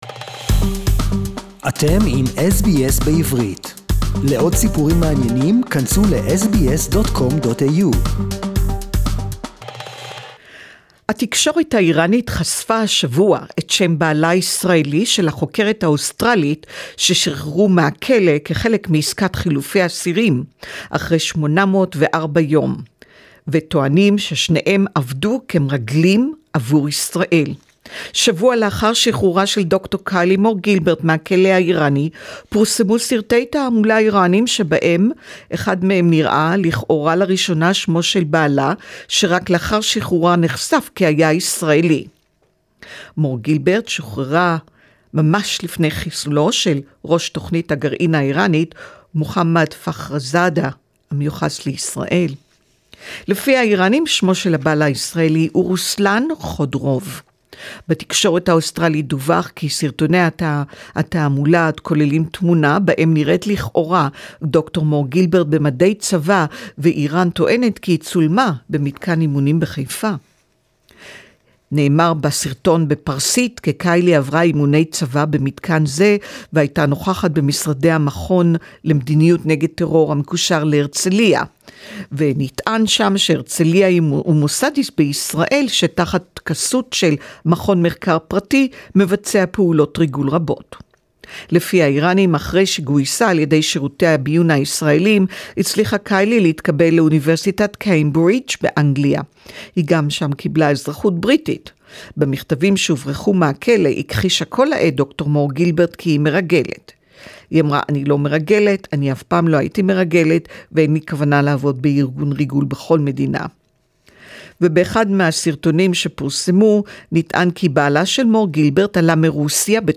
Report in Hebrew